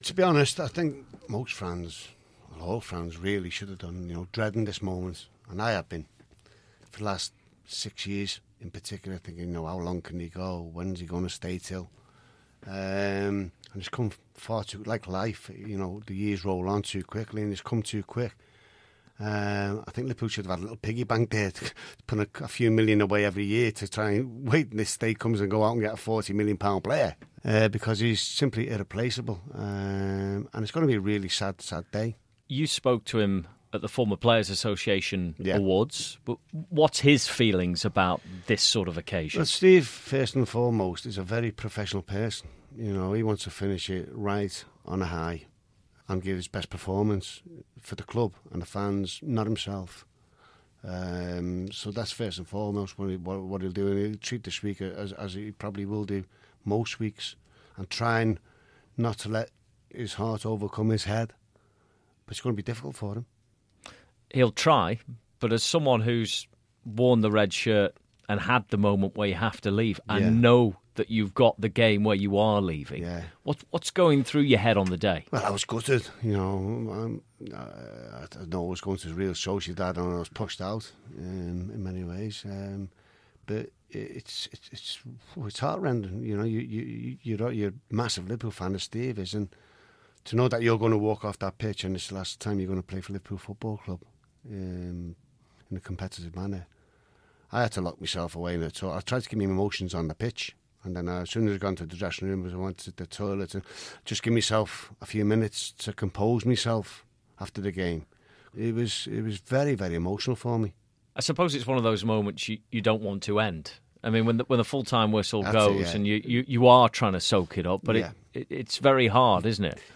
Radio City Sports John Aldridge speaks about Steven Gerrard ahead of his final game for Liverpool at Anfield